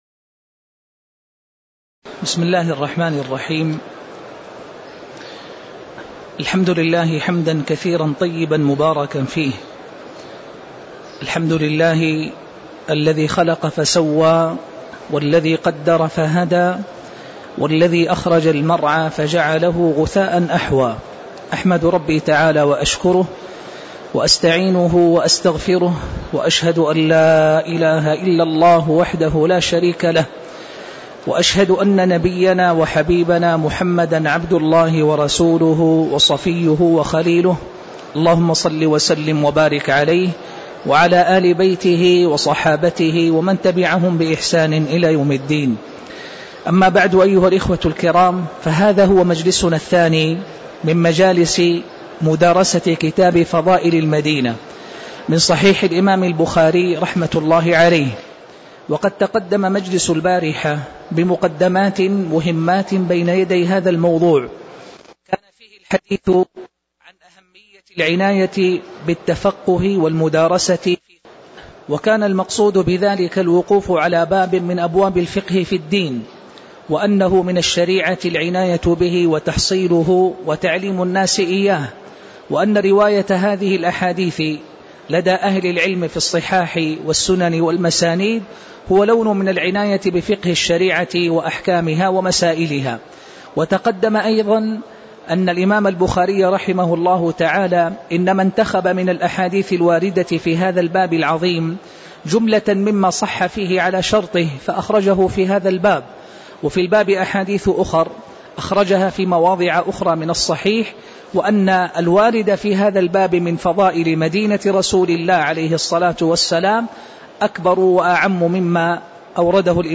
تاريخ النشر ٤ جمادى الآخرة ١٤٣٧ هـ المكان: المسجد النبوي الشيخ